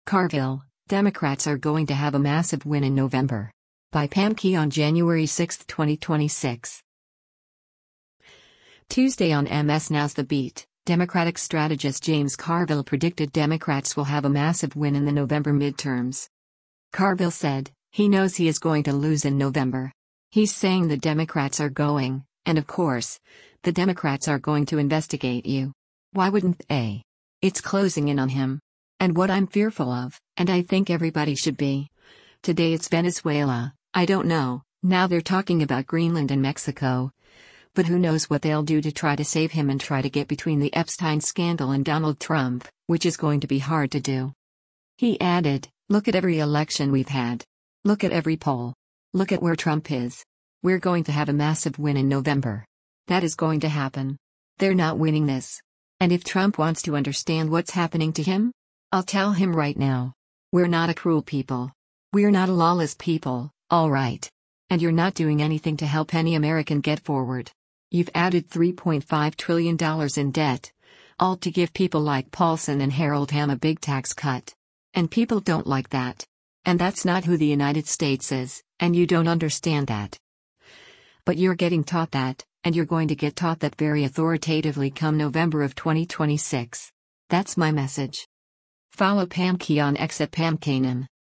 Tuesday on MS NOW’s “The Beat,” Democratic strategist James Carville predicted Democrats will “have a massive win” in the November midterms.